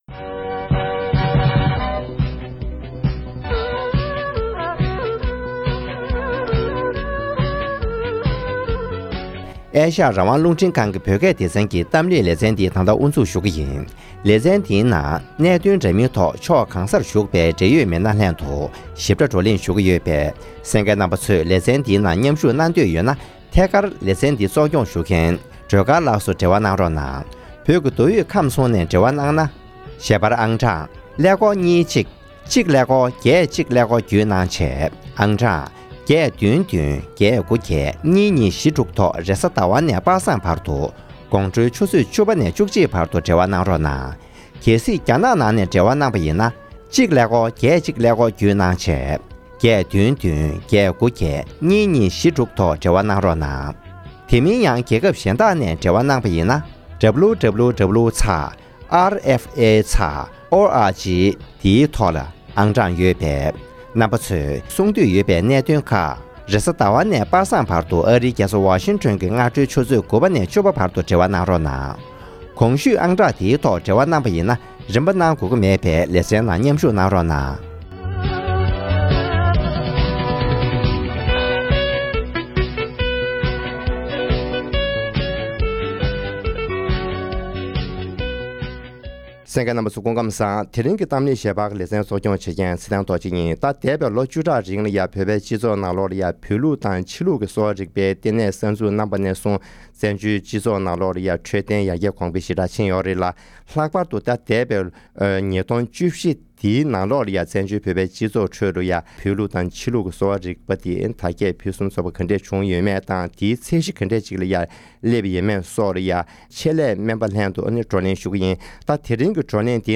༄༅༎ ཐེངས་འདིའི་གཏམ་གླེང་གི་ལེ་ཚན་ནང་།